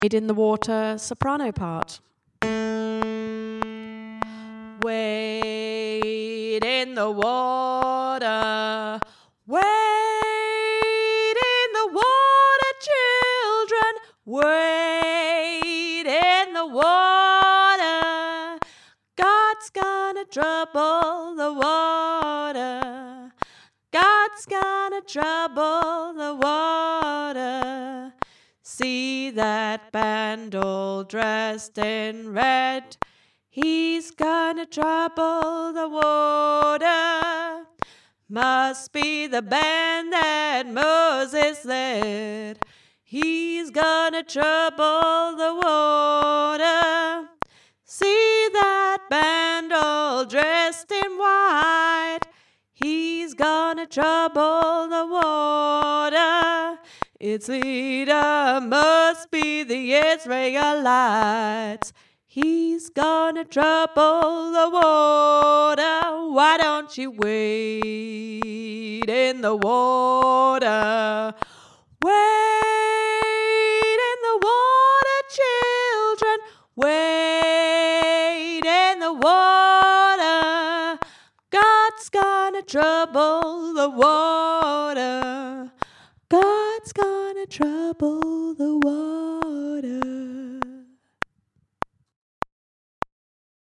wade-in-the-water-soprano - Three Valleys Gospel Choir
wade-in-the-water-soprano.mp3